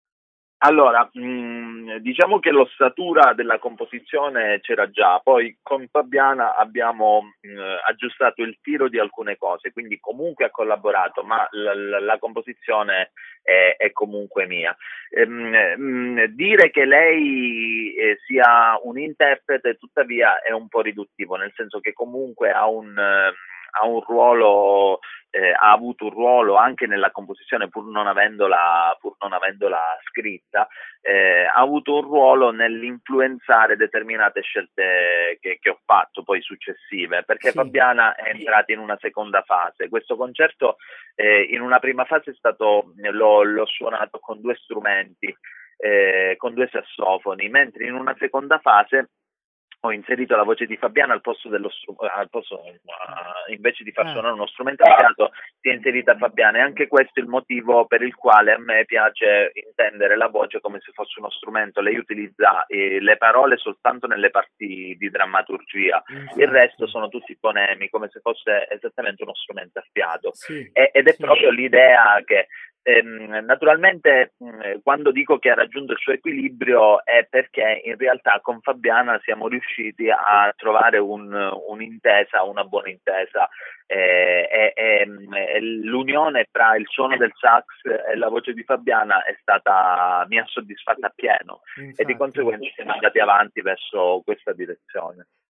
La telefonata